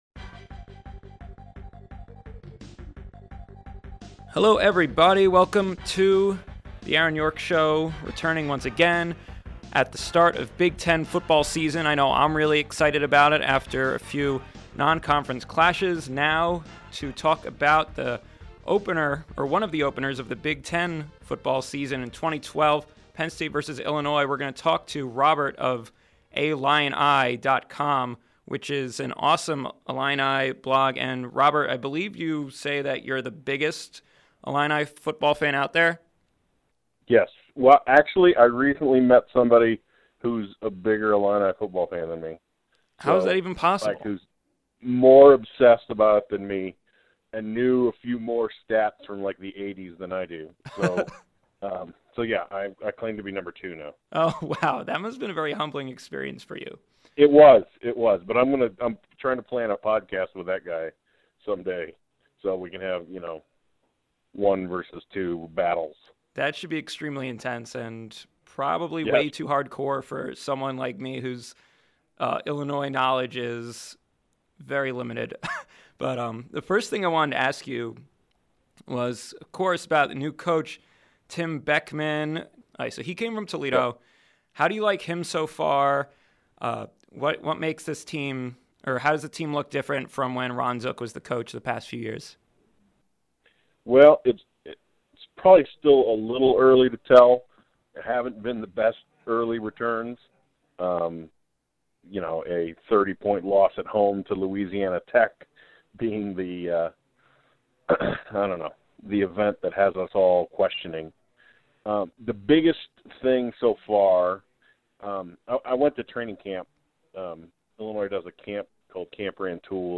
Podcast Interview with Illinois Blog “A Lion Eye”